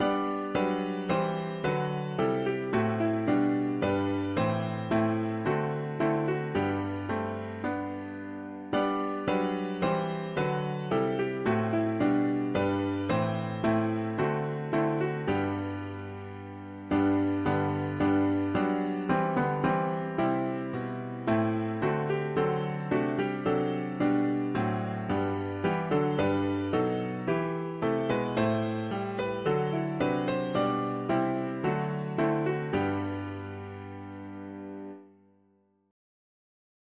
Each little flower blooming, each… english secular 4part chords